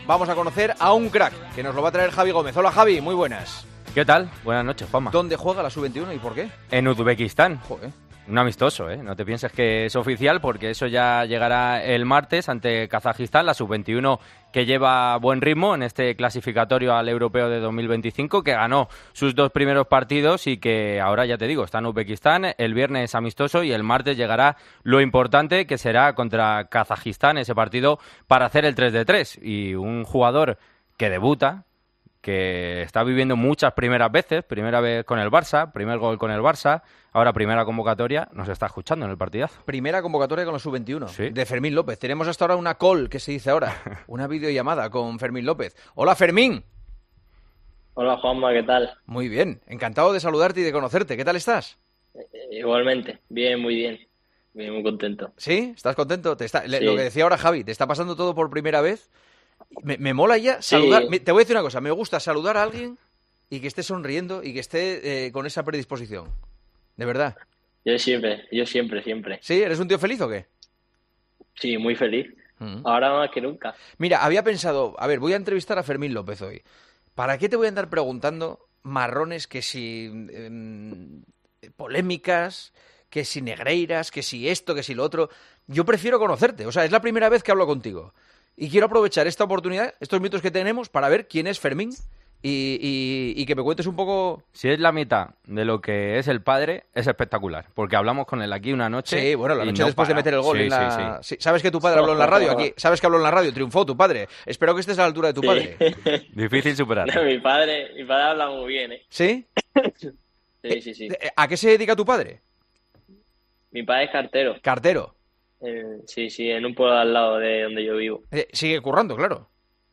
AUDIO: Juanma Castaño entrevistó al jugador del Barcelona que esta temporada ha debutado con el primer equipo y que ya se ha estrenado como goleador.